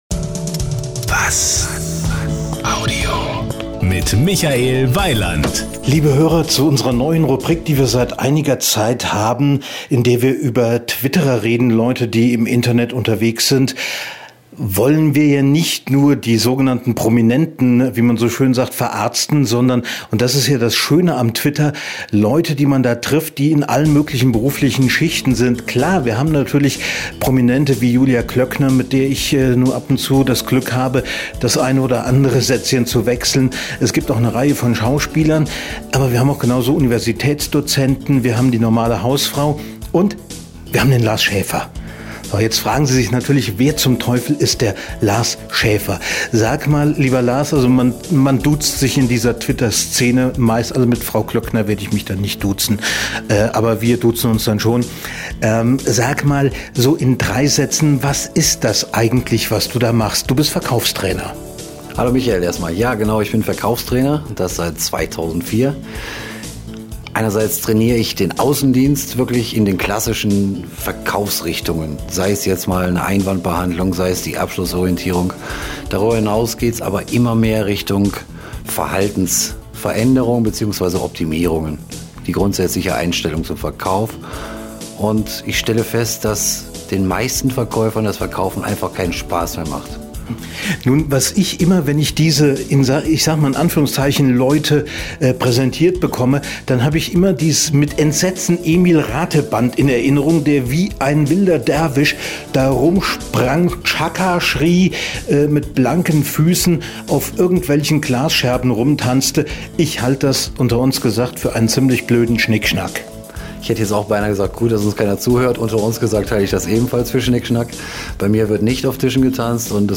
Hier gibt es das komplette Interview Länge: 25:00 min